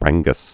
Bran·gus
(brănggəs)